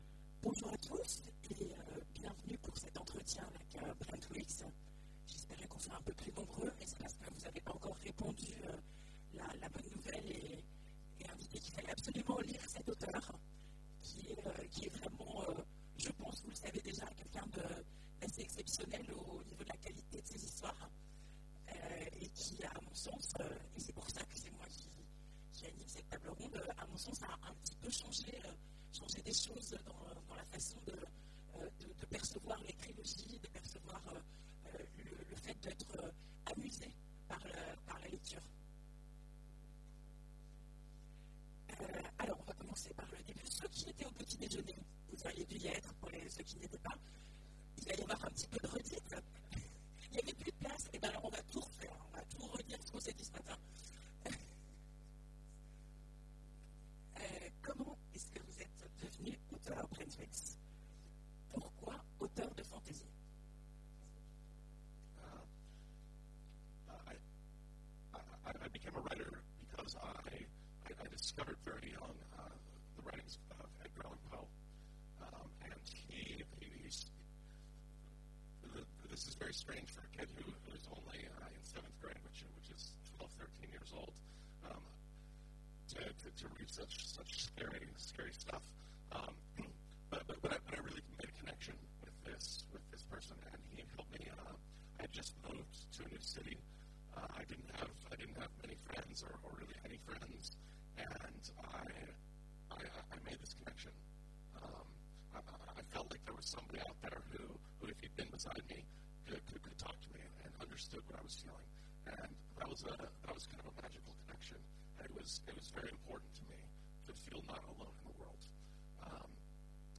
Imaginales 2015 : Rencontre avec Brent Weeks